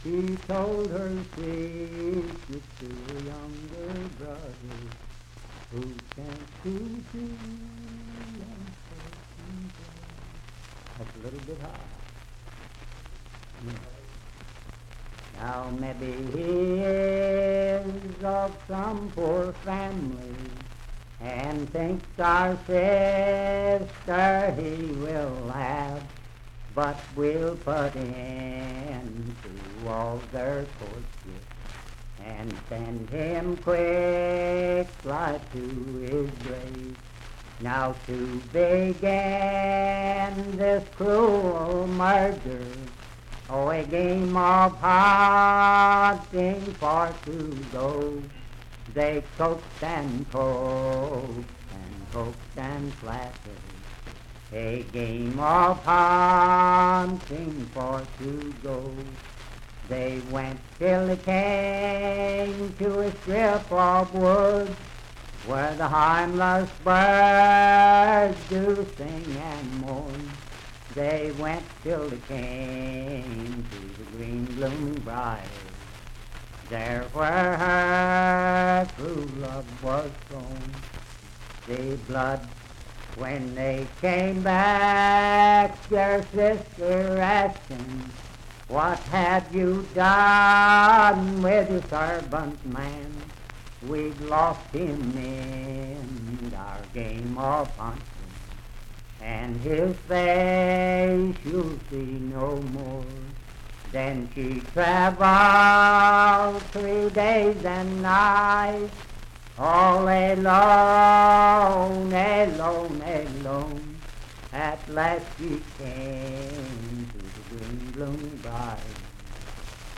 Verse-refrain 12(4).
Voice (sung)
Parkersburg (W. Va.), Wood County (W. Va.)